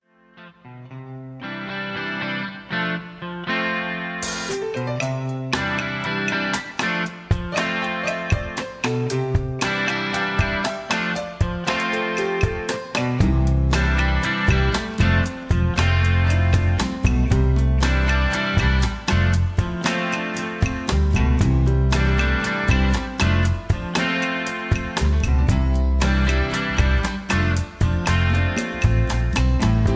Tags: studio tracks , backing tracks , soundtracks , rock